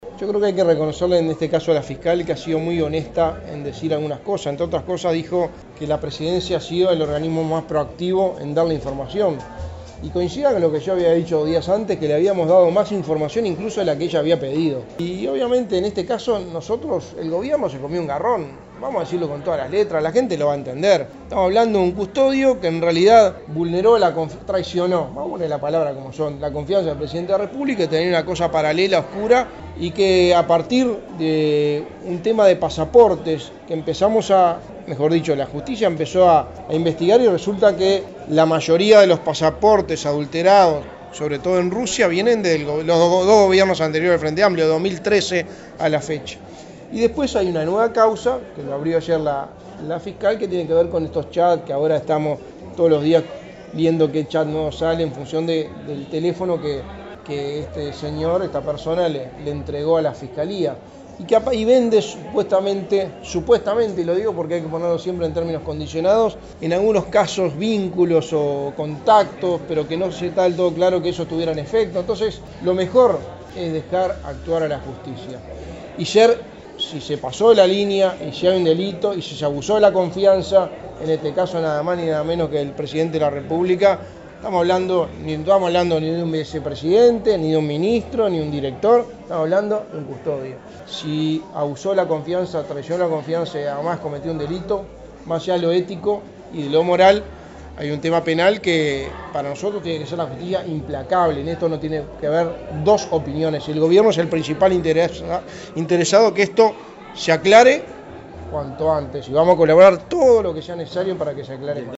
Delgado, en rueda de prensa durante la fiesta de fin de año de la agrupación Manuel Oribe que lidera Carlos Enciso, dijo que Fossati “ha sido muy honesta en decir algunas cosas.